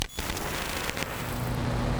Engine 4 Start.wav